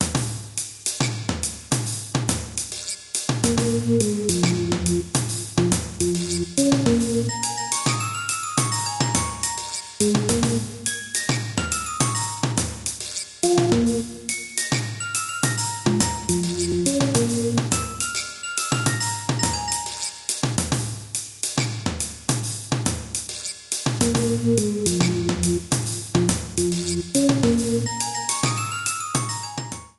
trimmed & added fadeout You cannot overwrite this file.